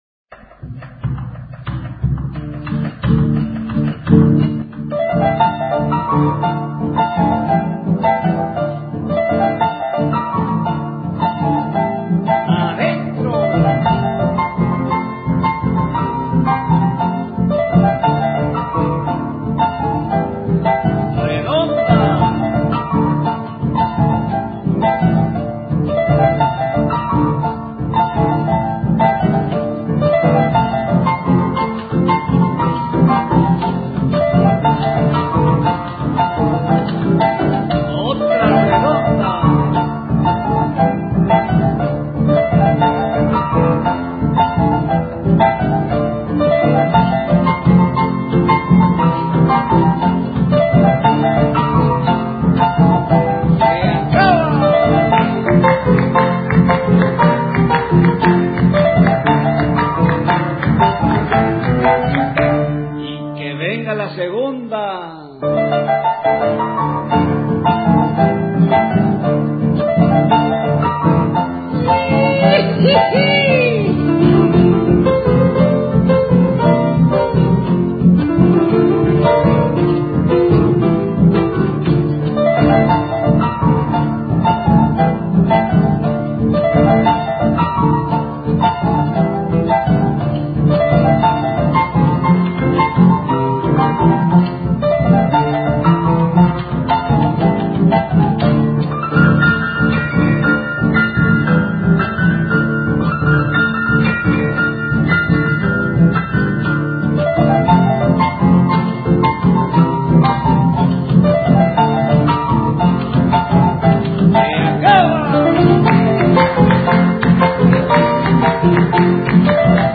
DANZAS FOLKLÓRICAS TRADICIONALES ARGENTINAS
CHACARERA